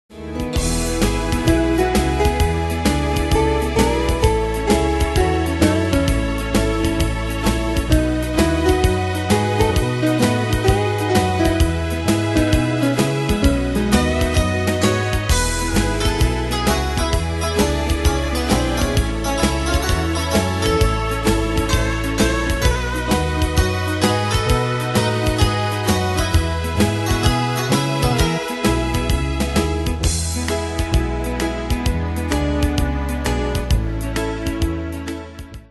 Style: Country Ane/Year: 1979 Tempo: 130 Durée/Time: 3.00
Danse/Dance: TripleSwing Cat Id.
Pro Backing Tracks